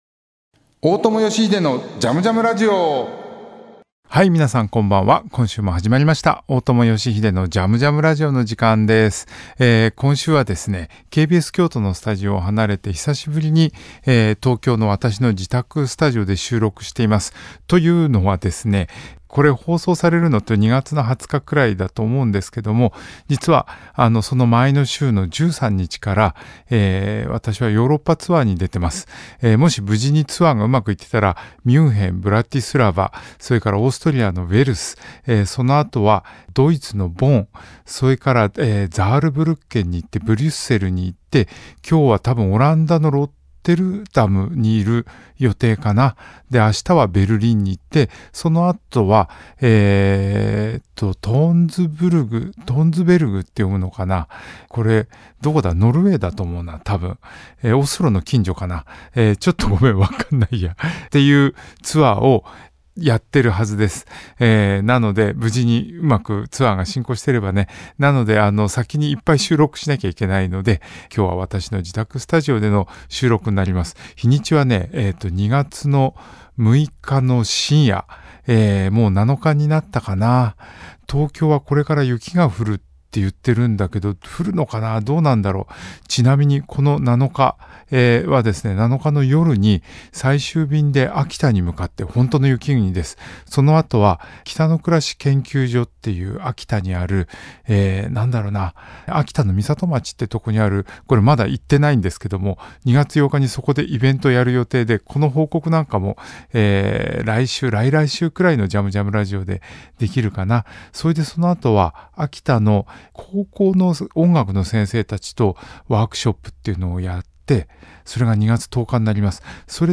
音楽家・大友良英がここでしか聞けないような変わった音楽から昔懐かしい音楽に至るまでのいろんな音楽とゲストを招いてのおしゃべりや、リスナーの皆さんからのリクエストやメッセージにもお答えしていくこの番組ならではのオリジナルなラジオ番組です。